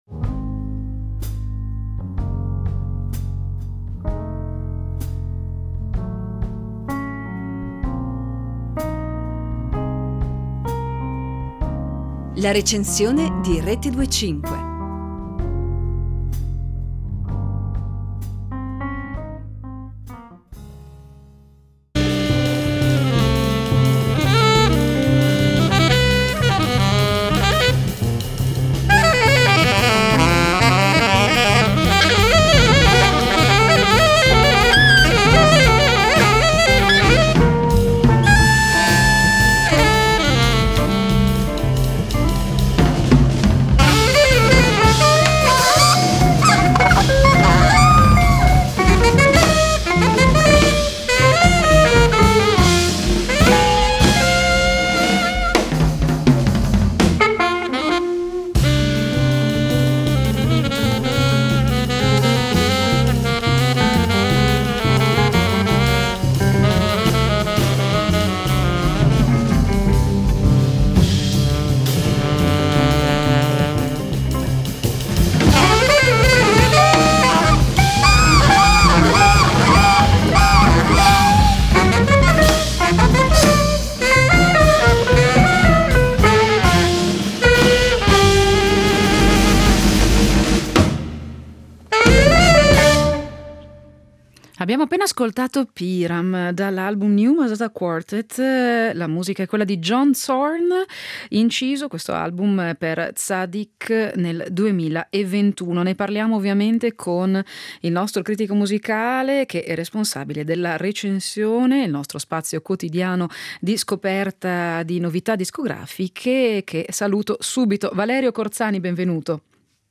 Ed è da lì che è arrivato il materiale per questa pubblicazione. Sono otto tracce, in un’entusiasmante combinazione di musica etnica e jazz, che offre diverse prospettive del suono sviluppato dal nuovo quartetto.